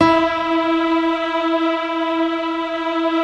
SI1 PIANO08L.wav